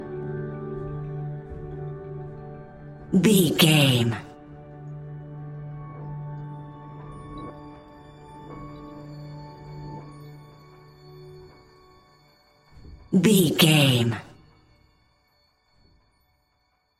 Ionian/Major
D♭
chilled
laid back
Lounge
sparse
new age
chilled electronica
ambient
atmospheric